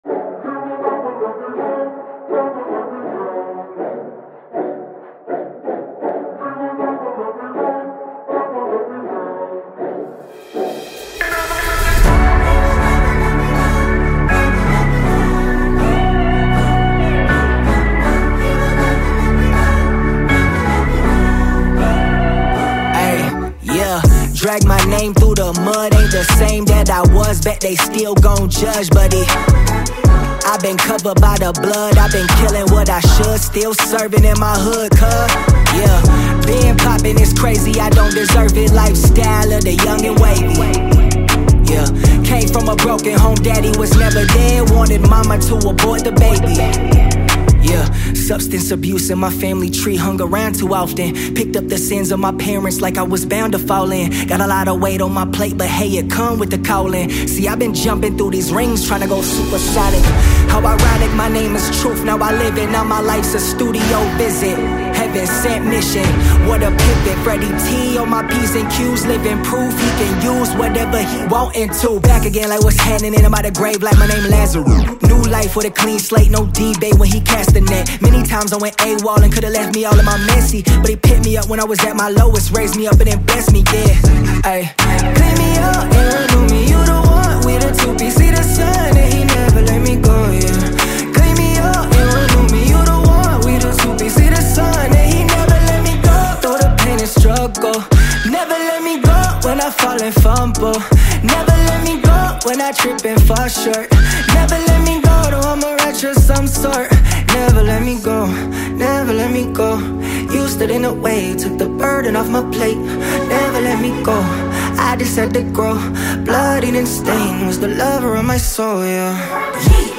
Mp3 Gospel Songs
heartfelt ballad
emotive delivery and soul-stirring vocals